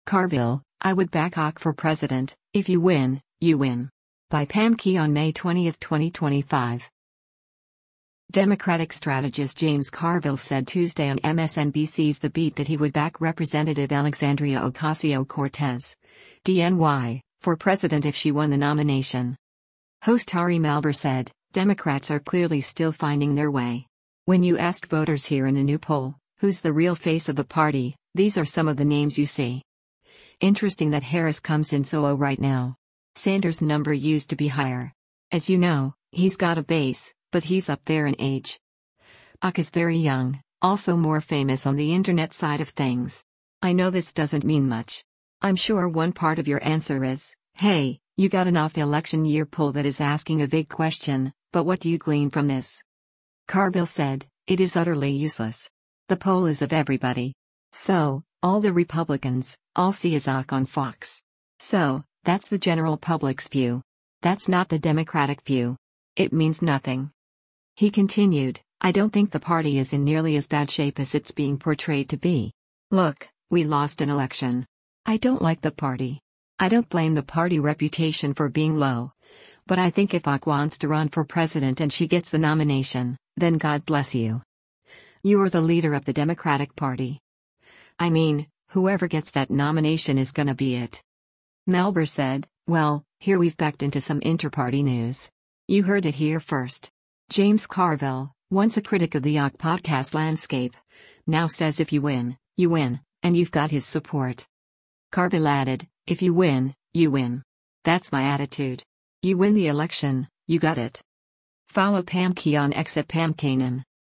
Democratic strategist James Carville said Tuesday on MSNBC’s “The Beat” that he would back Rep. Alexandria Ocasio-Cortez (D-NY) for president if she won the nomination.